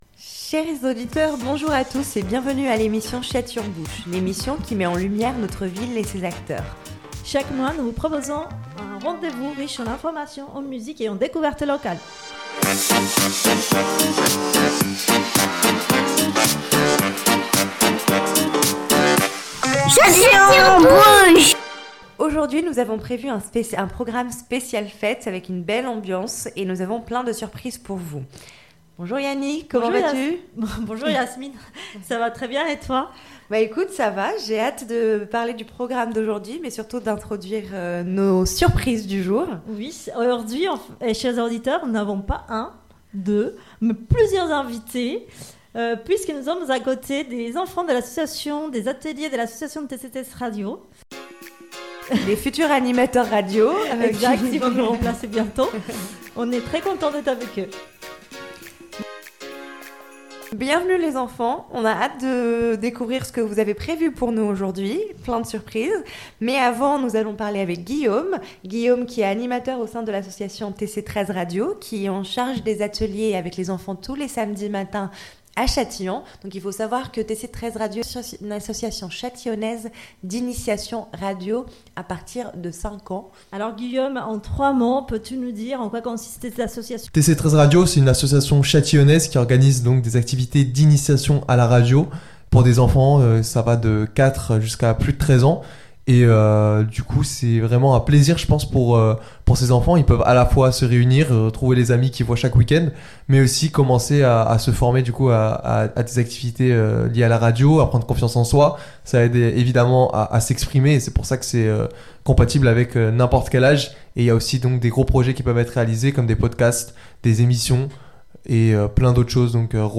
Et les jeunes voix pétillantes des ateliers radio de l’association TC13, qui partagent anecdotes, histoires enchantées et vœux pleins de sincérité pour illuminer cette période de fête.